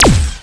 fire_quark.wav